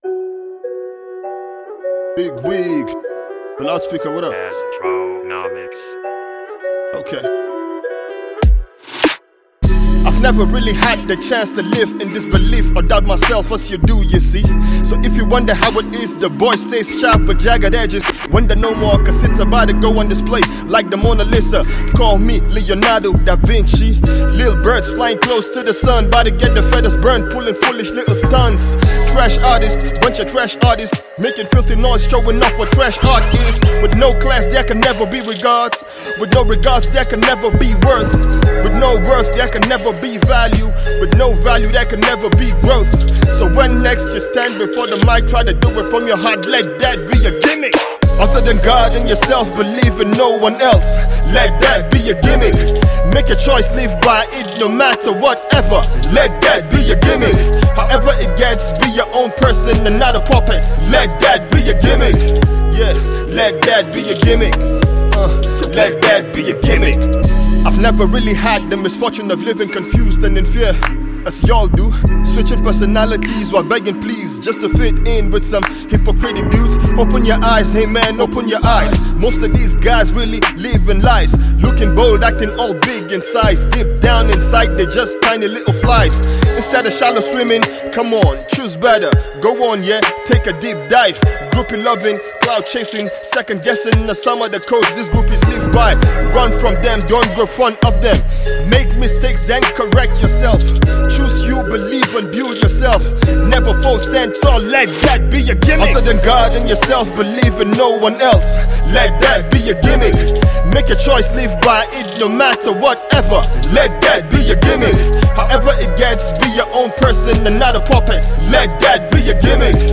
am a rap artist from Kaduna State.